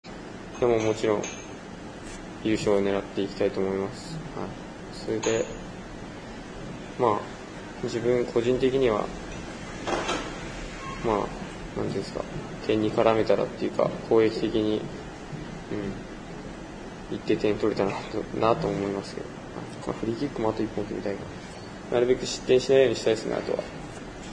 大会直前御殿場キャンプ・インタビュー